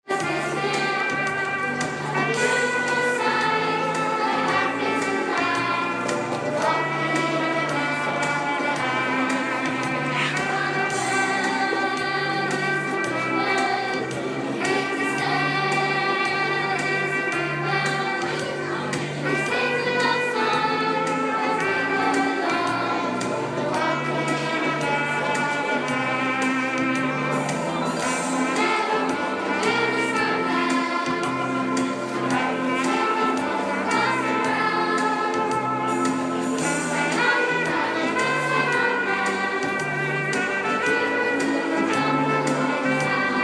Christmas Bazaar - Choir Performance